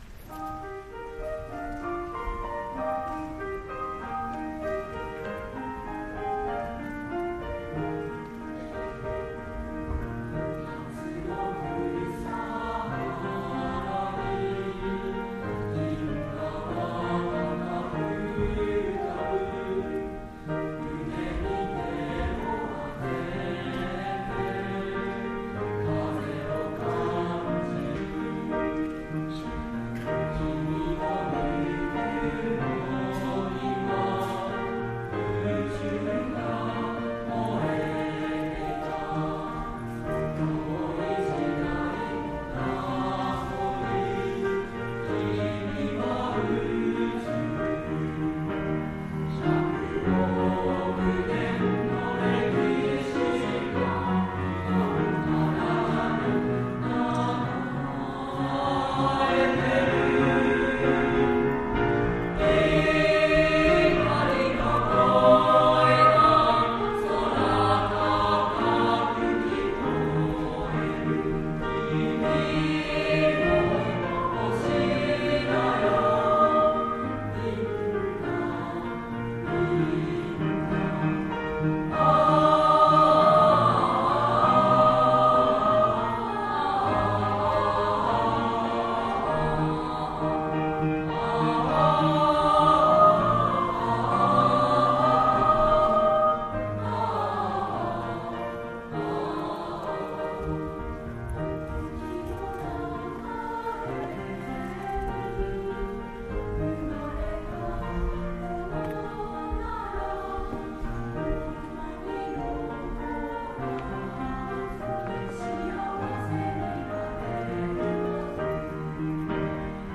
令和元年度 文化祭／合唱コンクールの歌声 【２年３組】
合唱コンクールでの２年３組の合唱を掲載しました。
＜２年３組／クラス合唱曲＞
作詞・作曲：ミマス（アクアマリン）／編曲：富澤 裕